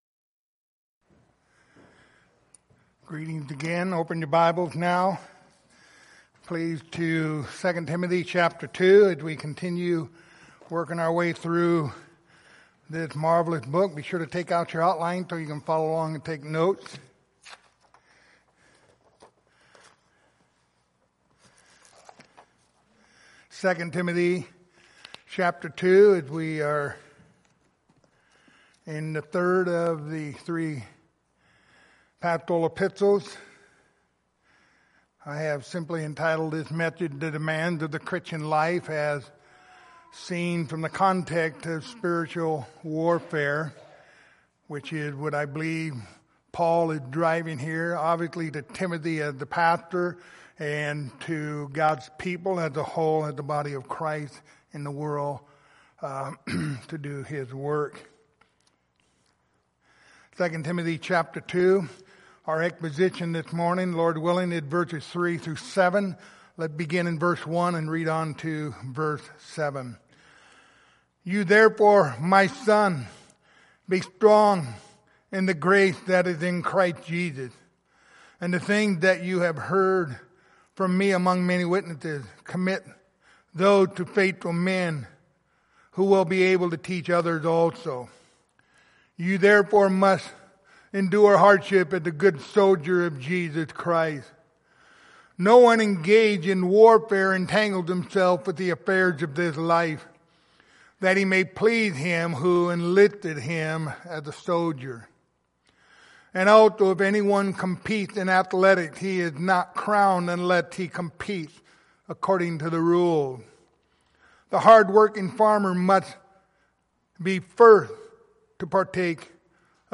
Passage: 2 Timothy 2:3-7 Service Type: Sunday Morning